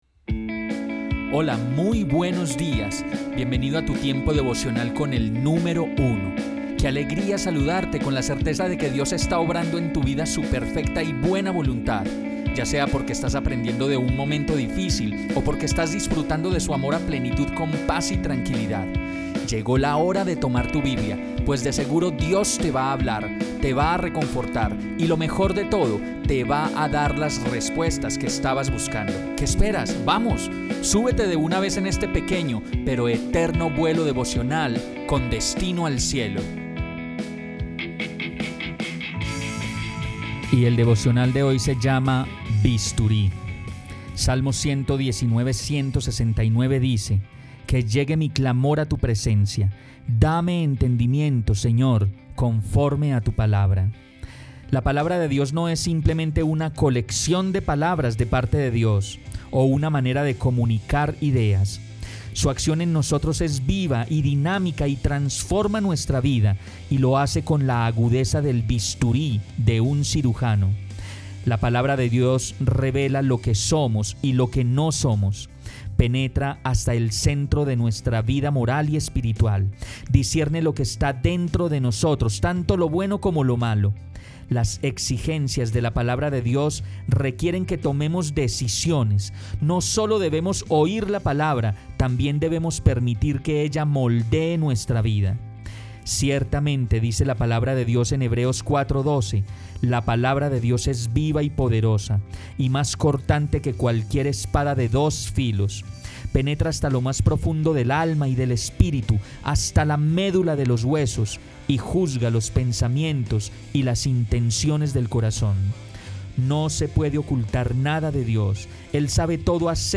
Devocional.